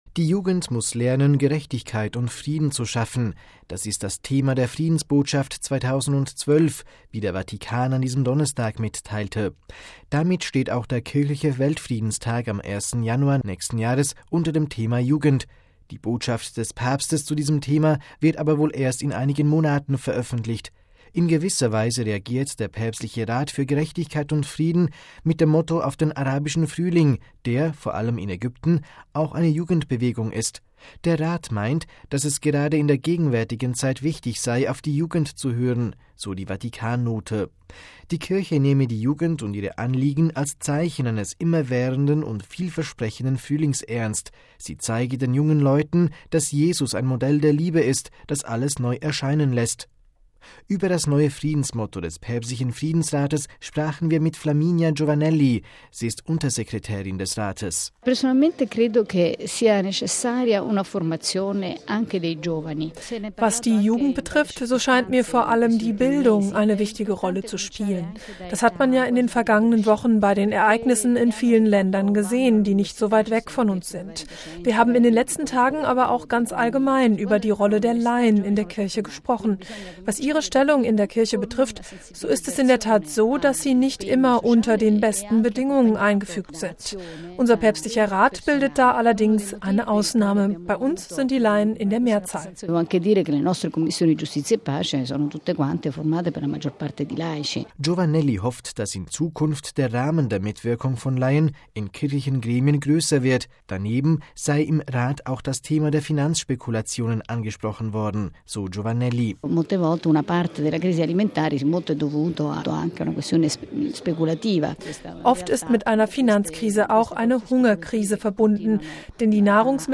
Über das neue Friedensmotto des Päpstlichen Friedensrates sprachen wir mit Flaminia Giovanelli. Sie ist Untersekretärin des Rates.